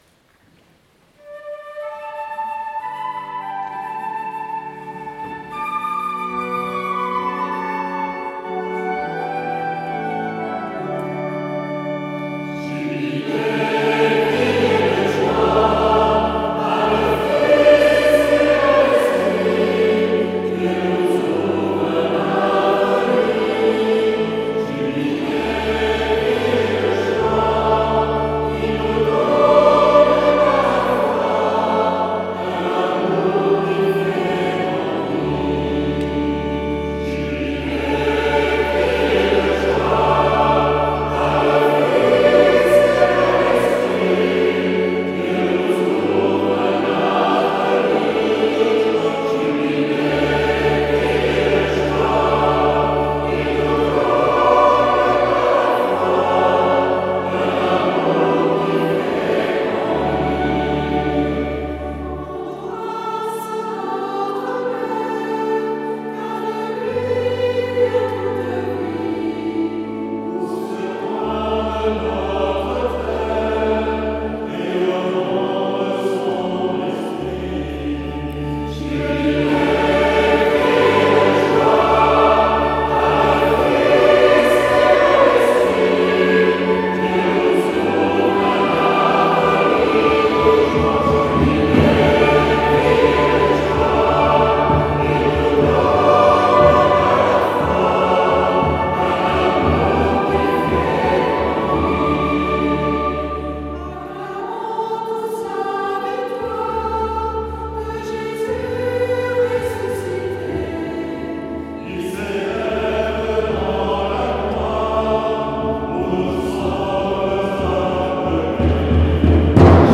Pour découvrir le chant « Jubilez, criez de joie », vous pouvez écouter cet enregistrement par le chœur Chant & Liturgie du diocèse, lors de l’Après-Midi Chant & Liturgie du 15 mars 2025 à l’église de Saint-Cyr-l’Ecole :